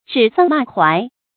注音：ㄓㄧˇ ㄙㄤ ㄇㄚˋ ㄏㄨㄞˊ
指桑罵槐的讀法